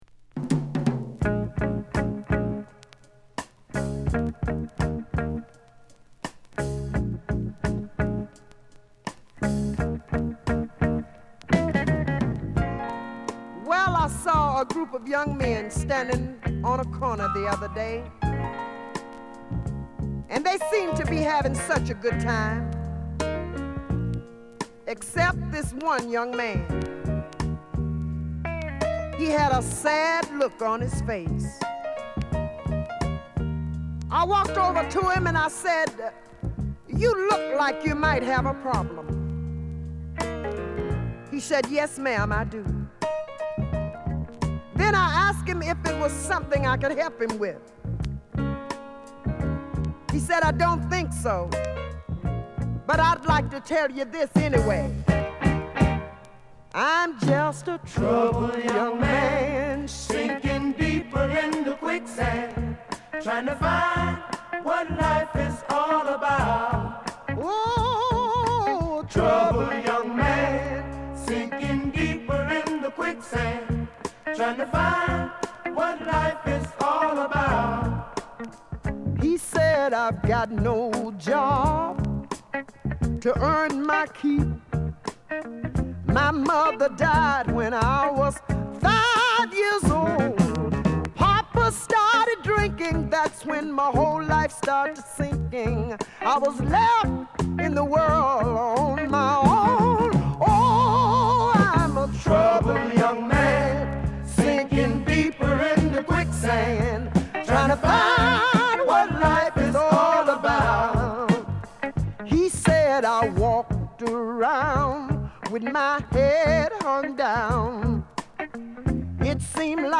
フィメール・ゴスペルシンガー
力強いゴスペルチューンを収録。